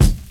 Wu-RZA-Kick 35.WAV